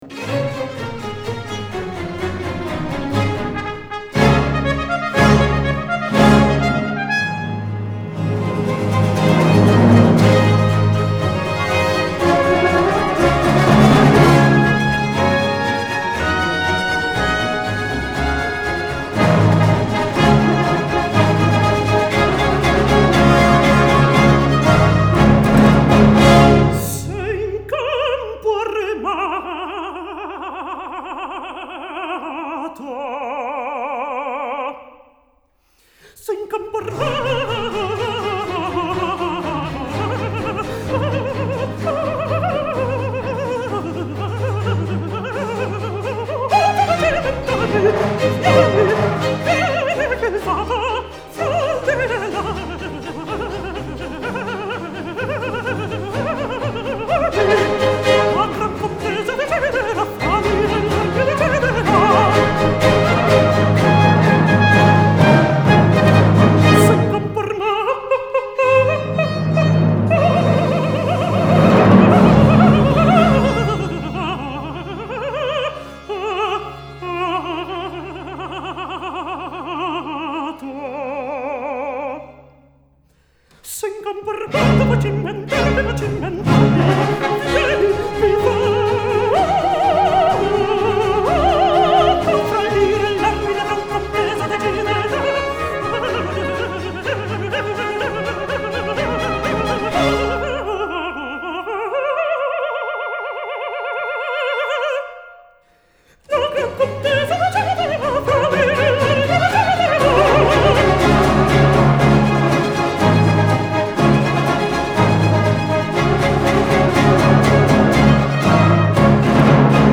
Aria - Se in campo armato